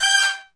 G5 POP FAL.wav